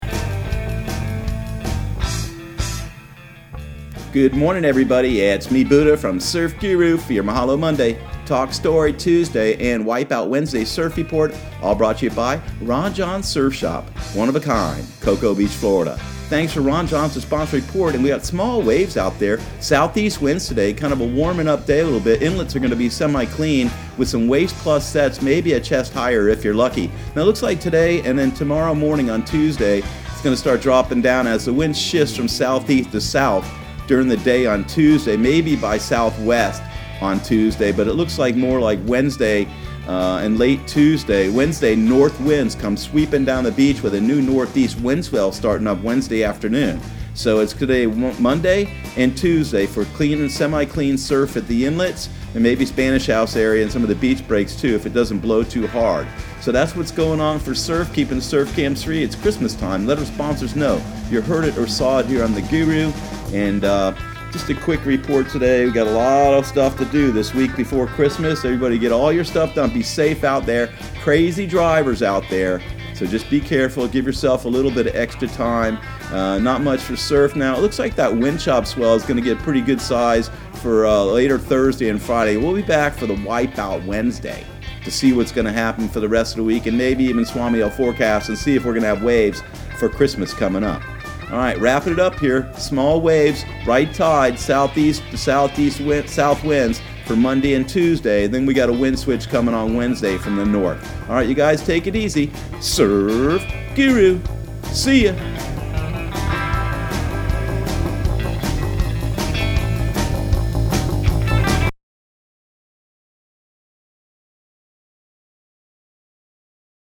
Surf Guru Surf Report and Forecast 12/16/2019 Audio surf report and surf forecast on December 16 for Central Florida and the Southeast.